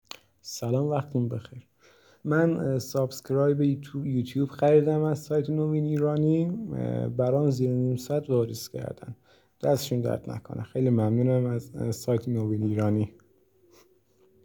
نظرات مثبت مشتریان عزیزمون با صدا خودشون رضایت از سایت نوین ایرانی